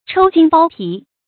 抽筋剝皮 注音： ㄔㄡ ㄐㄧㄣ ㄅㄠ ㄆㄧˊ 讀音讀法： 意思解釋： 形容剝削壓迫非常殘酷 出處典故： 清 劉鶚《老殘游記續集遺稿》第二回：「可知那州縣老爺們比娼妓還要下賤！